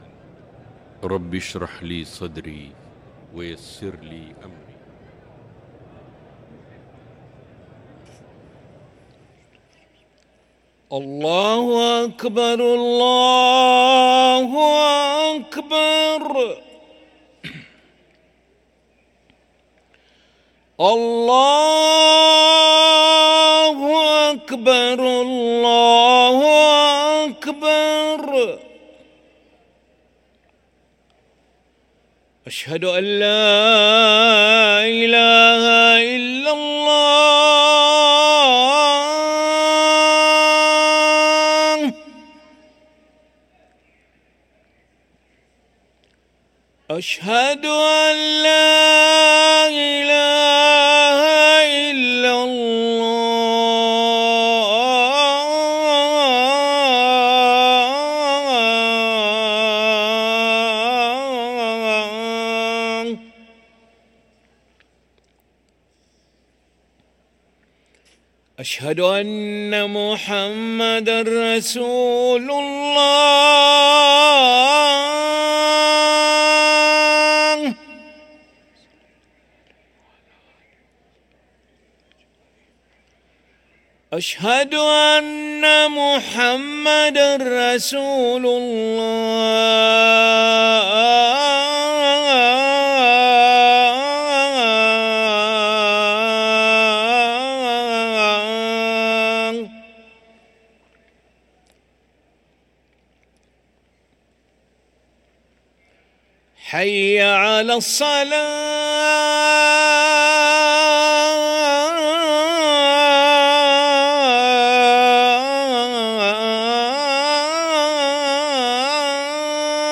أذان العشاء للمؤذن علي ملا الأحد 26 محرم 1445هـ > ١٤٤٥ 🕋 > ركن الأذان 🕋 > المزيد - تلاوات الحرمين